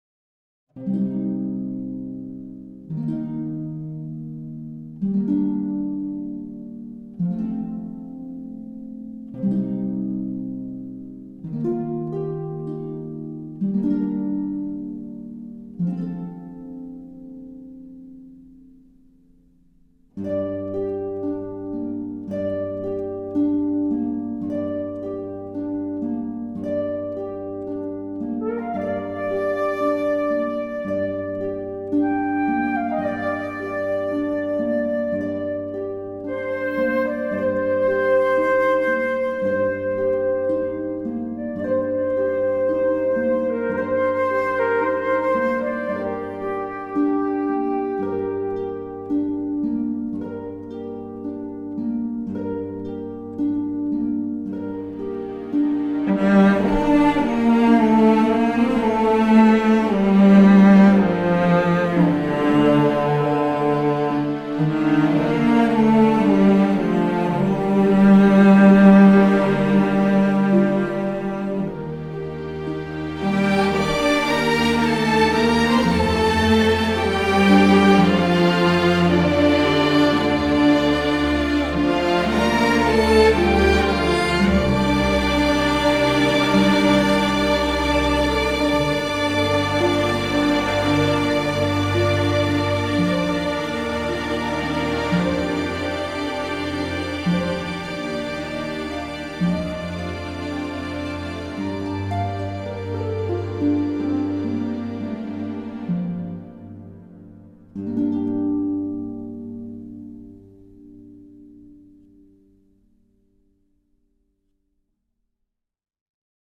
tema dizi müziği, duygusal hüzünlü rahatlatıcı fon müziği.